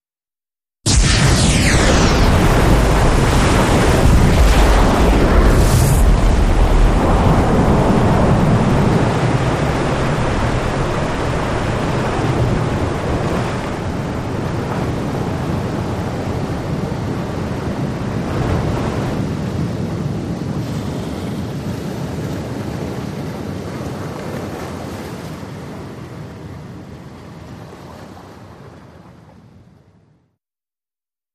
Depth Charge
Explosion, Surface Perspective Depth Charge Multiple Ver. 1